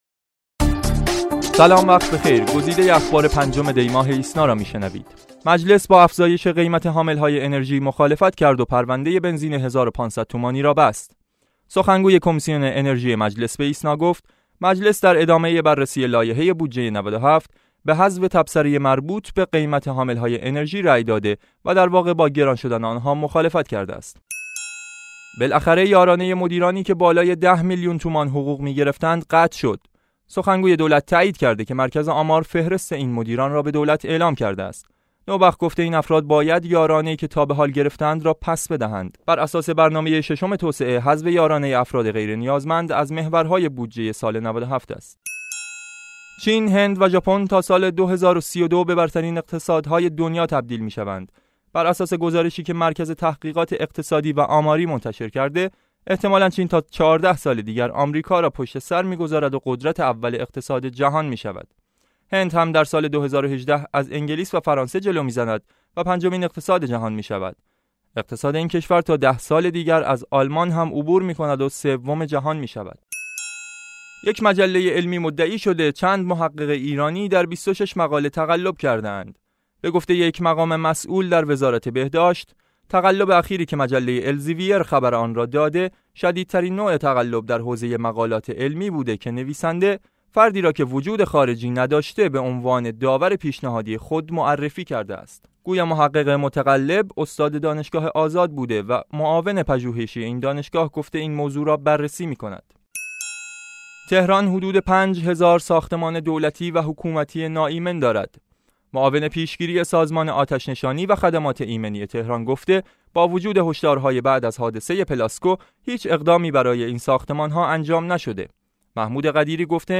صوت / بسته خبری ۵ دی ۹۶